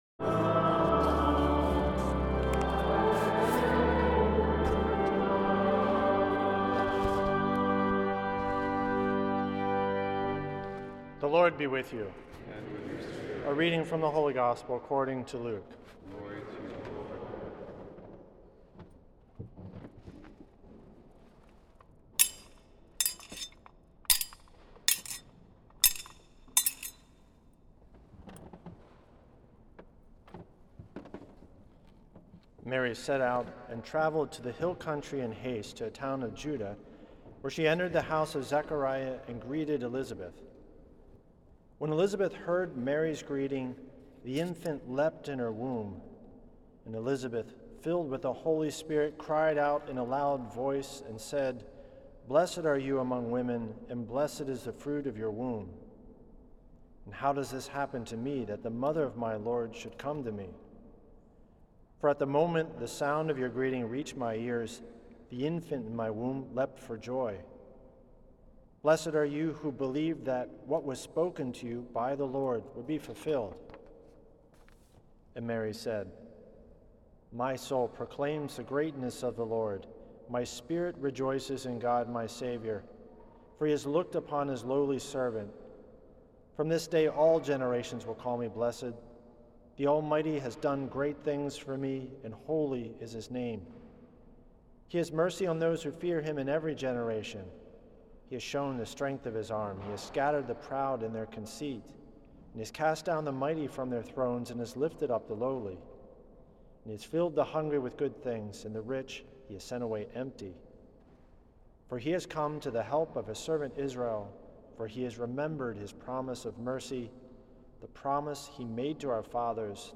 Homily
at St. Patrick’s Old Cathedral in NYC